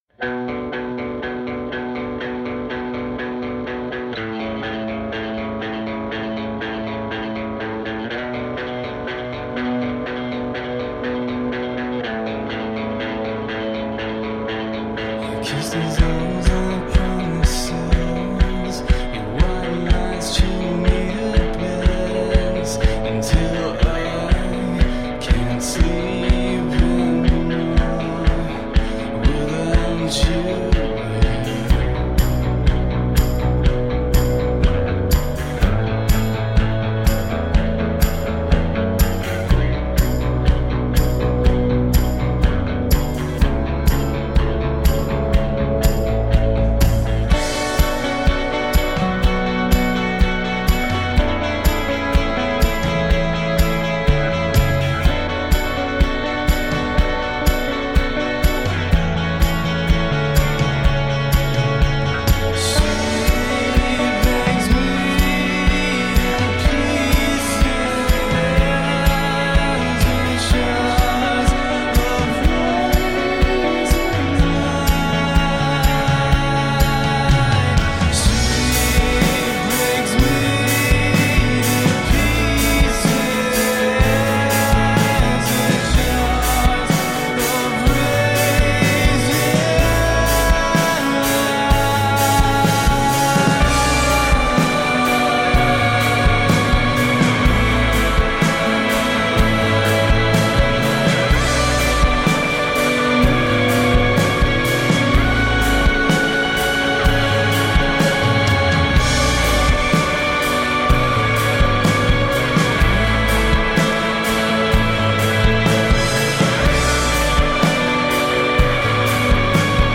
Melodic fury meets post-punk, shoegaze, alternative.
The sound is clean, precise, full and warm.
Tagged as: Alt Rock, Darkwave